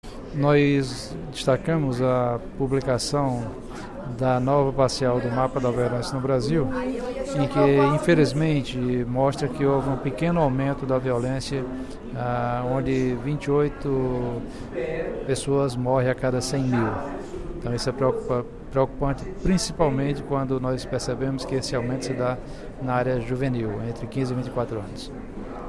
No primeiro expediente da sessão plenária desta quarta-feira (28/05), o deputado Professor Pinheiro (PT) destacou a prévia do Mapa da Violência divulgado na última terça-feira (27/05).